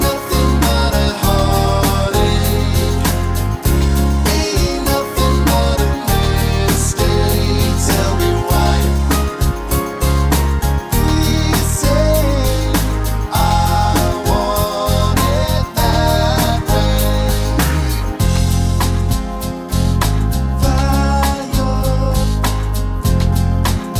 One Semitone Down Pop (1990s) 3:33 Buy £1.50